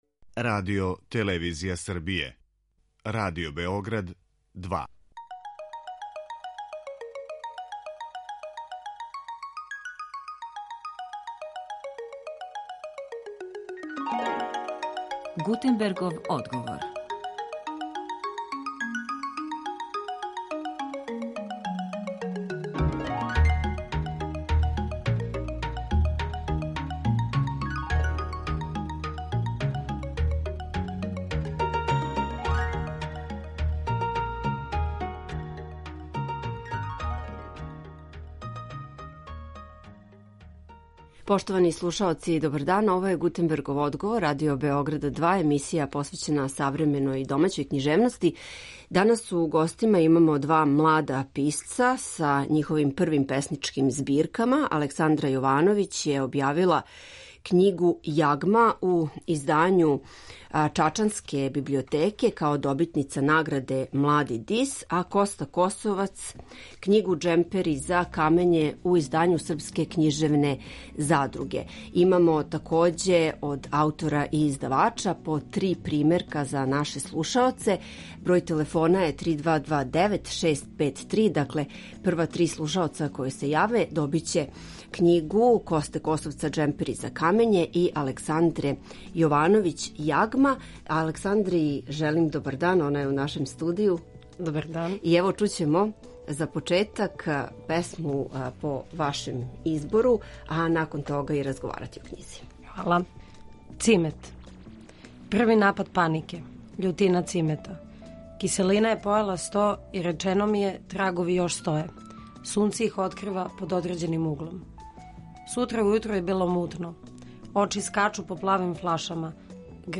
О томе када су одлучили да пишу поезију, како виде поезију данас, како долазе до „својих" тема, и о доживљају поводом појаве својих првих књига, између осталог, разговарамо са нашим гостима.